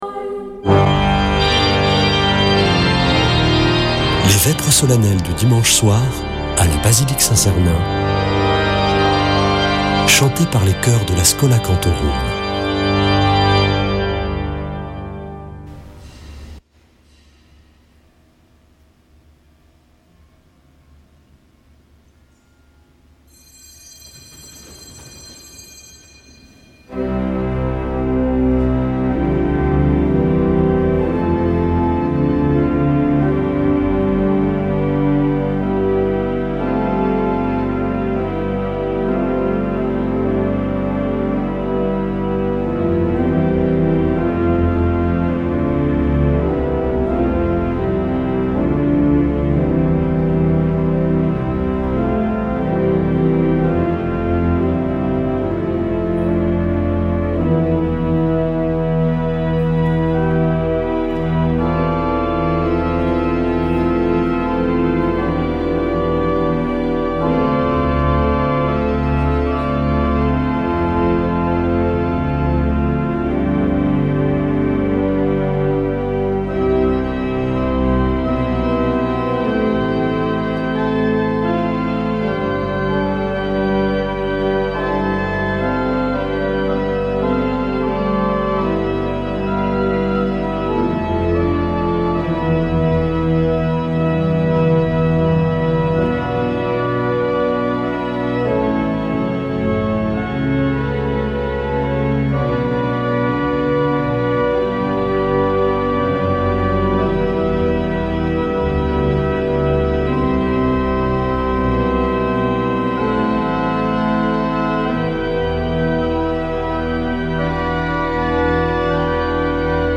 Vêpres de Saint Sernin du 21 déc.